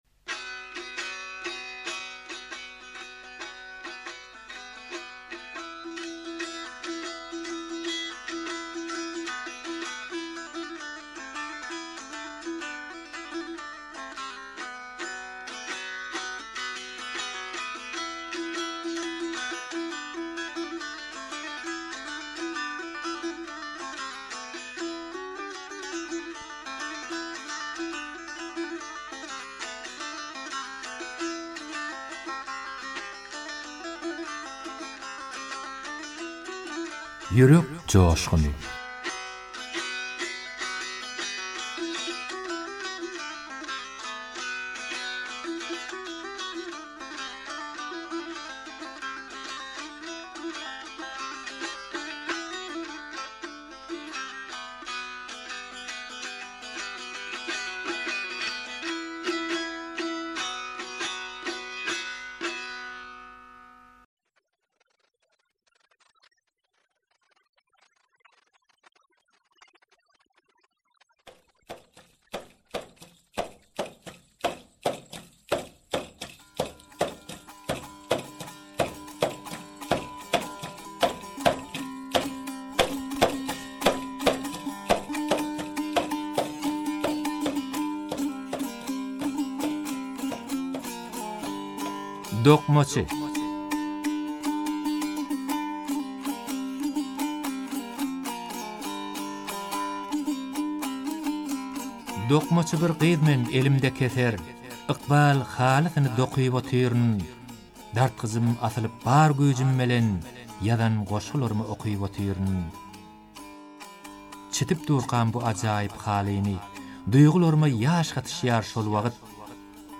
turkmen goşgy owaz aýdym şygyrlar